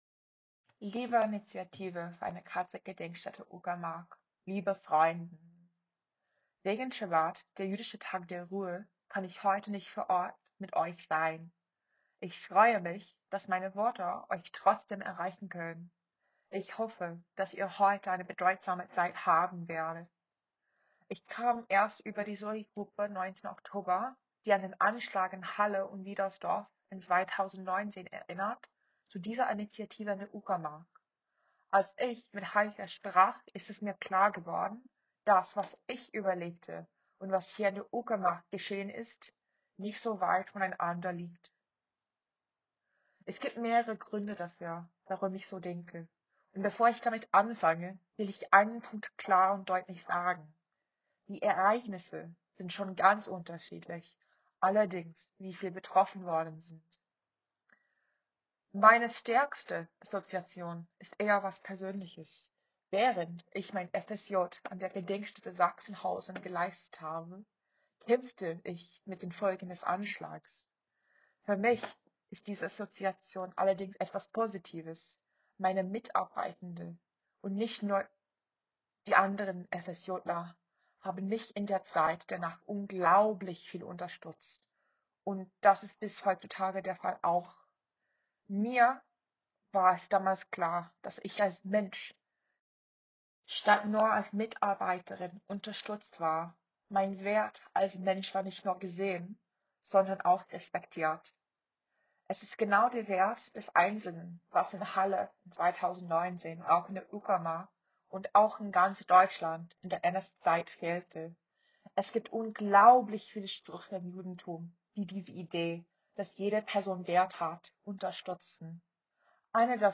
Redebeiträge zum 80. Jahrestag der Befreiung des KZ Uckermark
Am 3. Mai 2025 fand die Gedenkfeier zum 80. Jahrestag der Befreiung des KZ Uckermark statt – im Gedenken an die Frauen* und Mädchen*, die 1942—1945 im Jugendkonzentrationslager und 1945 im Vernichtungsort gequält und ermordet wurden.